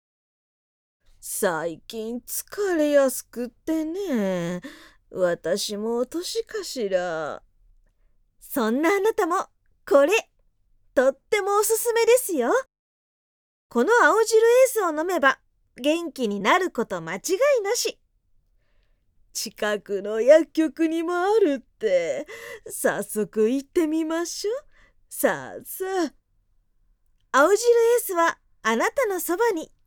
ボイスサンプル
明るめナレーションとおばあさん